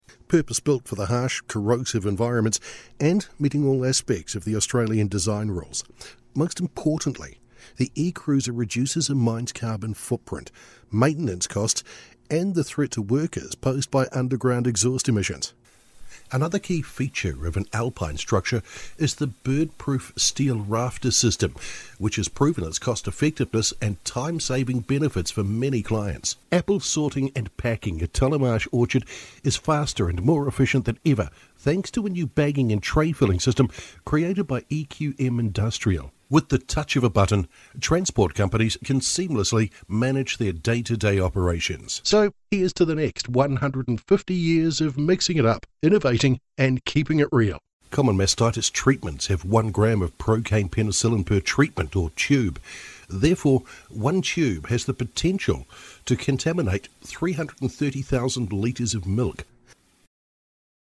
A New Zealand Voice Talent with a sound that’s warm, believable and buttery smooth.
Working from a home based studio in the lower North Island of New Zealand we are able to offer quick turnaround of voice overs, demos, auditions and guide tracks.
Narration